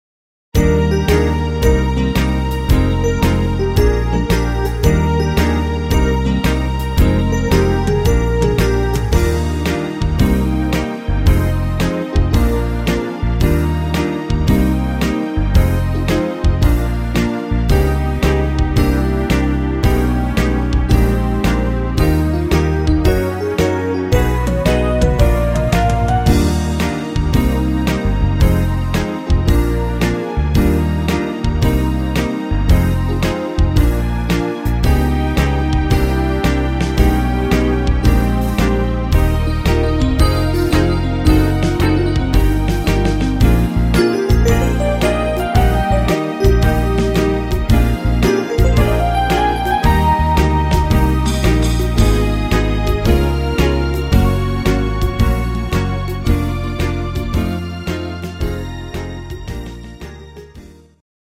Bar Piano